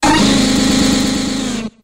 986cry.mp3